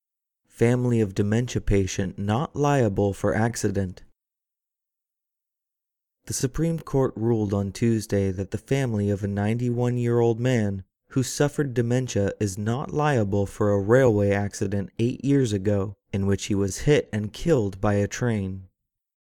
ネイティブ音声のリズムや抑揚に気を付け、完全にコピーするつもりで通訳トレーニングを反復してくださいね。